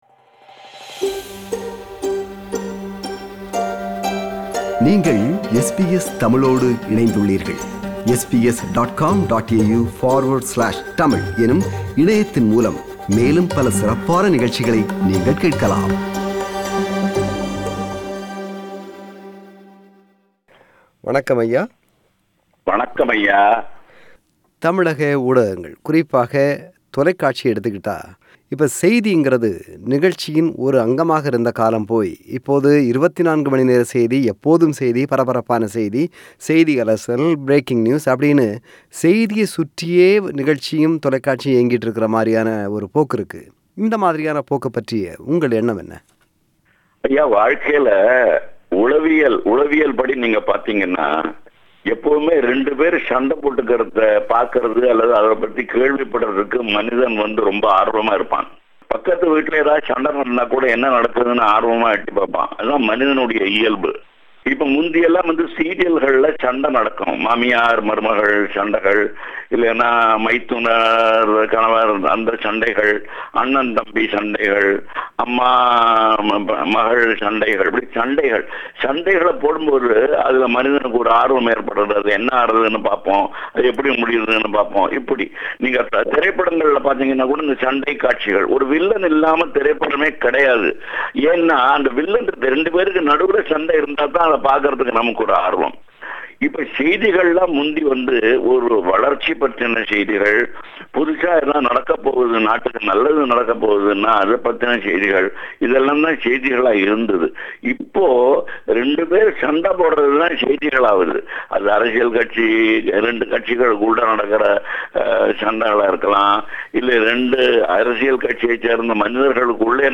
நேர்முகம் பாகம் 1